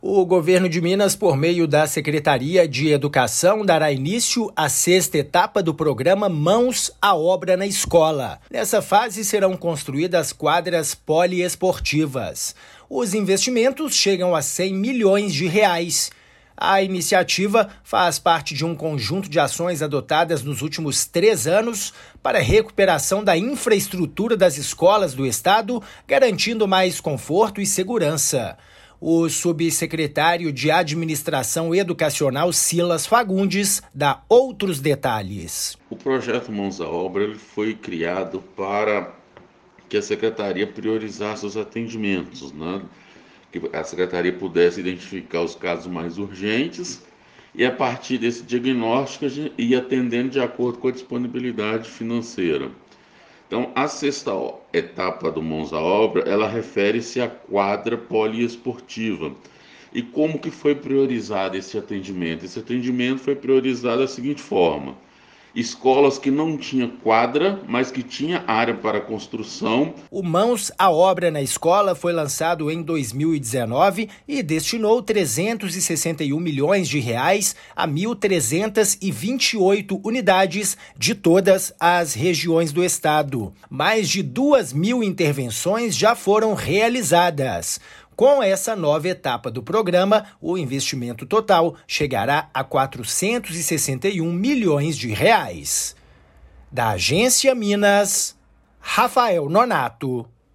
Serão investidos R$ 100 milhões nesta 6ª etapa do Mãos à Obra na Escola, programa da Secretaria de Estado de Educação (SEE/MG). Ouça a matéria de rádio.